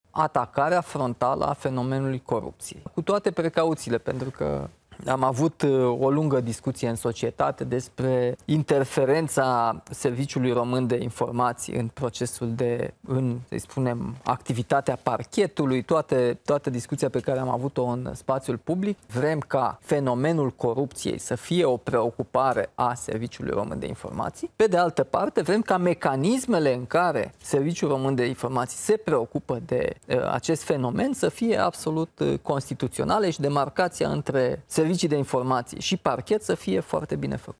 „Sunt două schimbări majore: corupția și războiul hibrid”, a explicat Nicușor Dan, într-un interviu pentru Știrile ProTv